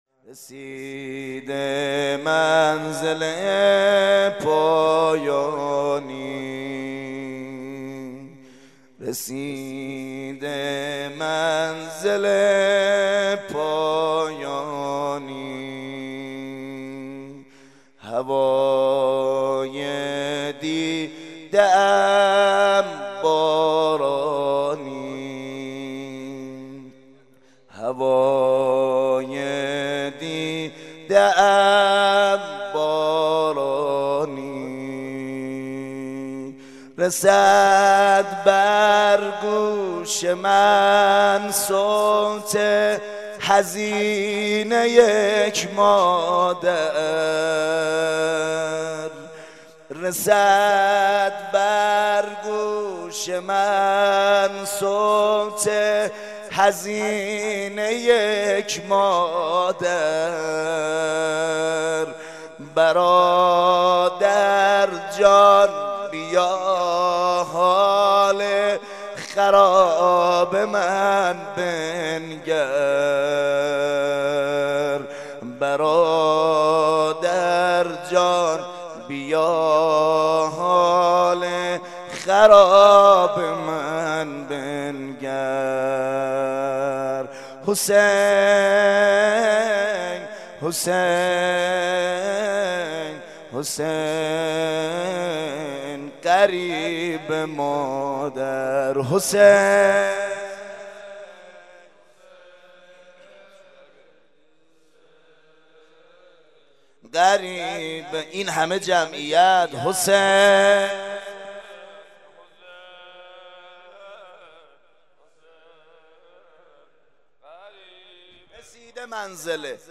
سینه زنی جهاد